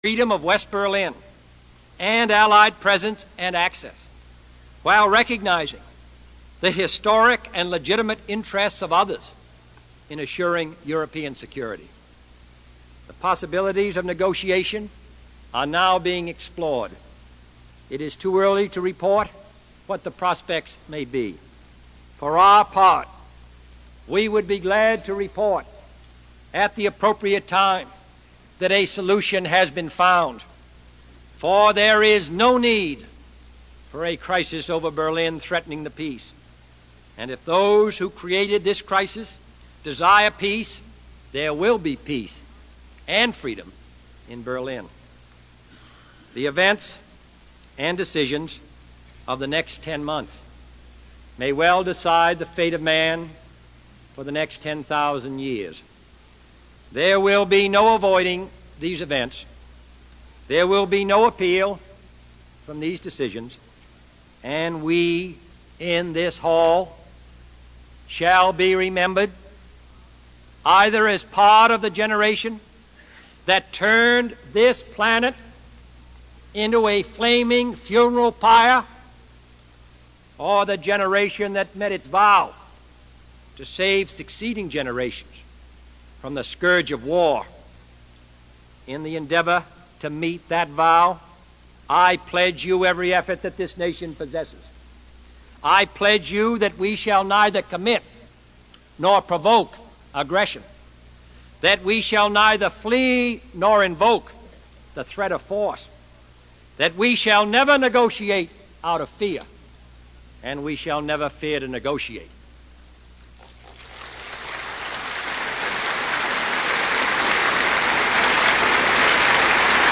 Category: Politics   Right: Personal
Tags: John F. Kennedy John F. Kennedy Address United Nations John F. Kennedy speech President